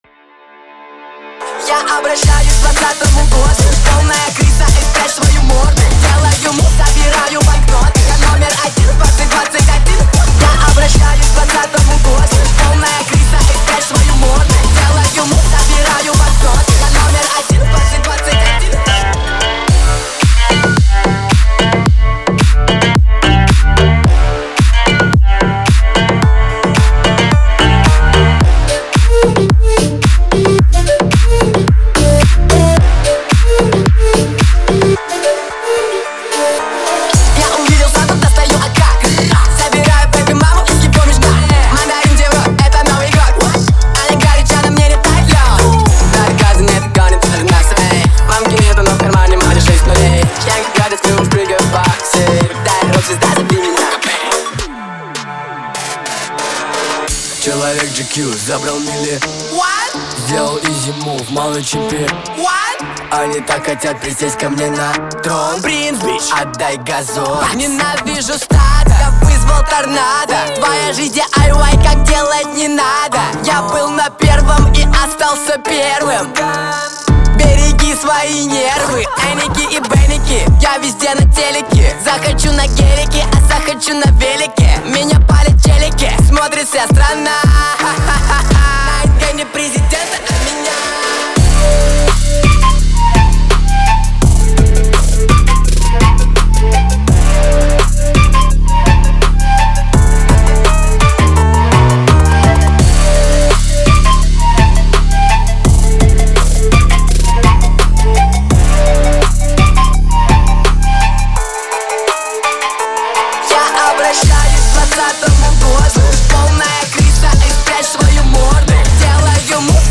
это энергичный трек в жанре поп с элементами EDM